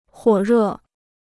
火热 (huǒ rè): fiery; burning.